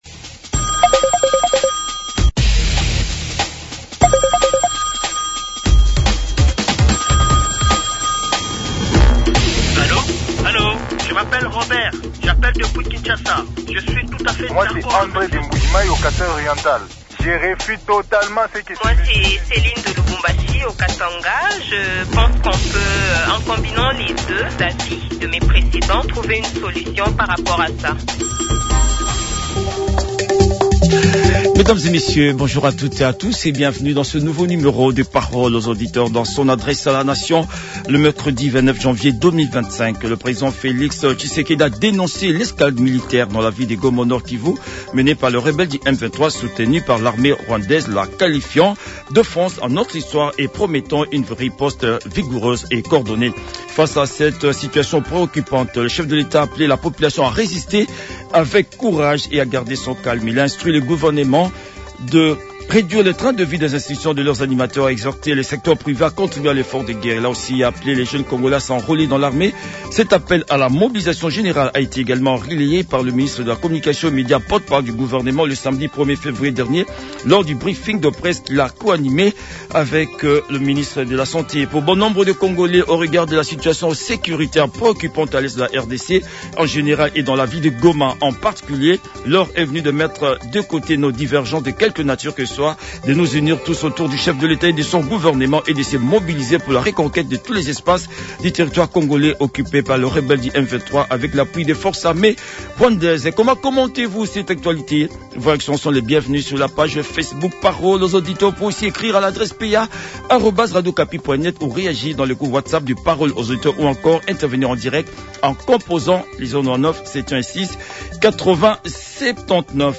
Les auditeurs ont échangé